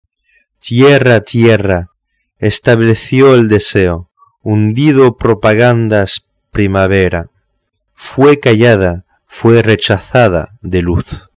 POUR ENTENDRE LA PRONONCIATION EN ESPAGNOL